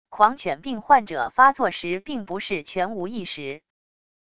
狂犬病患者发作时并不是全无意识wav音频生成系统WAV Audio Player